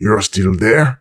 woodboxdestroyed07.ogg